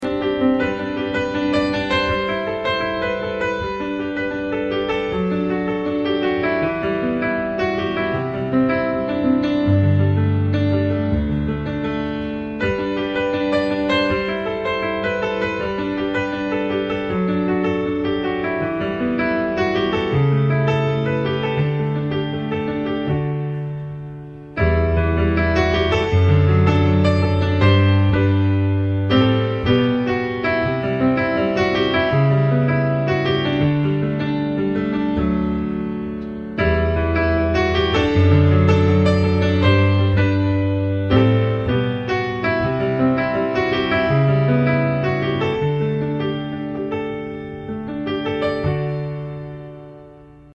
[TUTO] : le traitement son sur un piano acoustique
voilà pour rendre ce son-là un peu "plus moderne" (pas forcément meilleur!!), plus variété et lui donner plus d'espace, j'ai fait plusieurs petits traitements mais chacun est assez discret; c'est l'ensemble qui crée ensuite le son finale
-- j'ai mis une petite compression assez douce avec un compresseur opto (très peu de réduction, donc threshold assez haut); ratio de 5,1 avec un attack de 10msec (pour bien garder les attaques du jeu) et un release de 200msec
-- ensuite une égalisation sur laquelle j'ai monté un peu le grave autour de 80Hz, atténué un mid autour des 800Hz et puis j'ai relevé un peu le haut entre 5kHz et 12kHz pour ajouter plus de brillance
une plate courte (1,2sec) et très scintillante ce qui ajoute de la brillance au son
et une Hall assez longue (presque 3sec) et plutôt sombre (dark hall) ce qui ajoute un peu d'ampleur et d'espace
le son est évidemment devenu un peu plus artificiel et a perdu le côté "gras naturel"; mais c'est évidemment aussi au gout d'un chacun de trouver une sonorité satisfaisante par rapport à son projet !! mon exemple est juste une variante parmi plein d'autres....